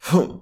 takedamage5.ogg